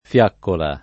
fiaccola [ f L# kkola ]